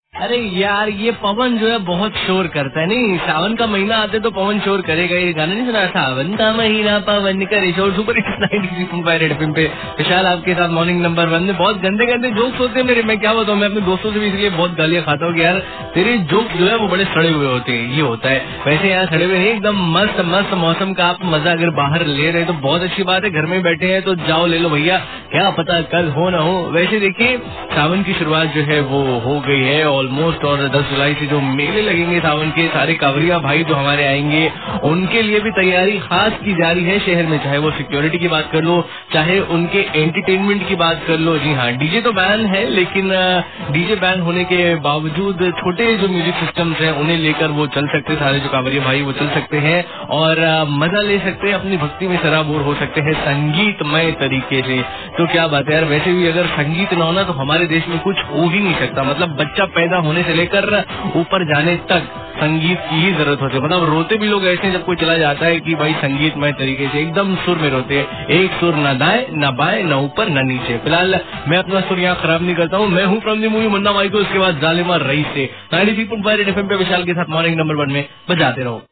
Rj about sawan mela